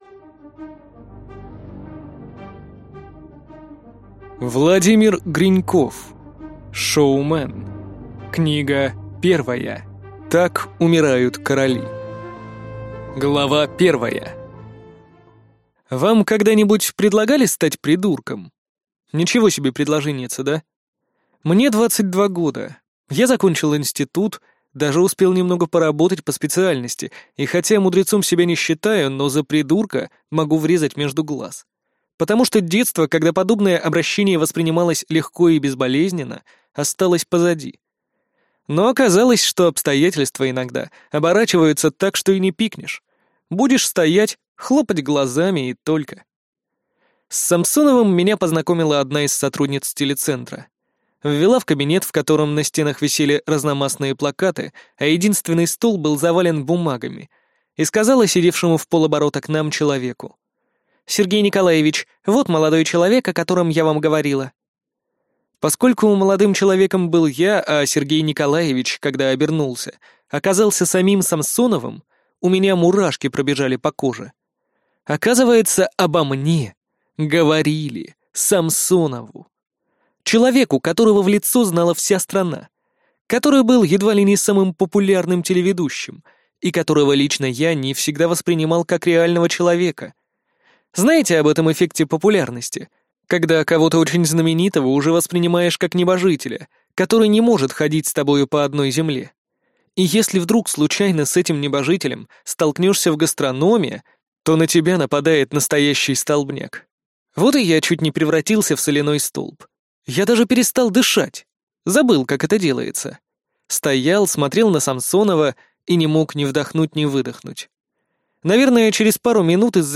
Аудиокнига Шоумен. Так умирают короли | Библиотека аудиокниг